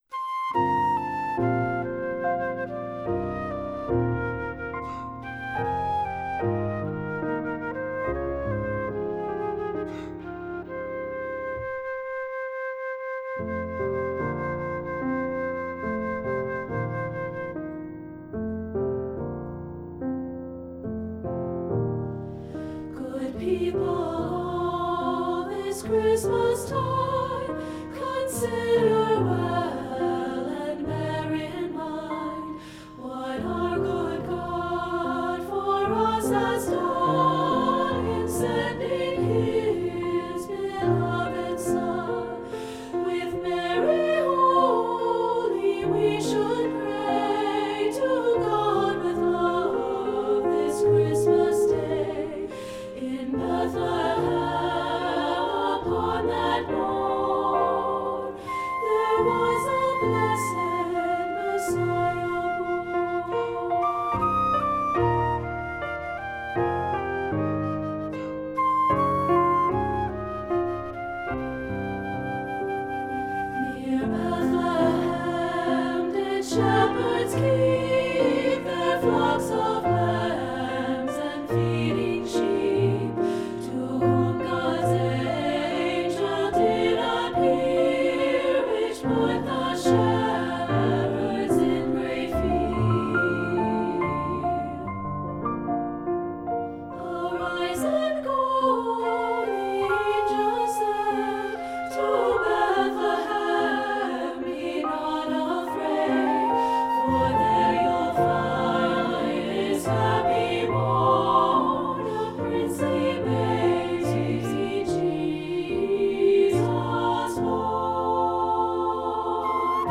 Composer: Traditional Enlgish Carol
Voicing: SSA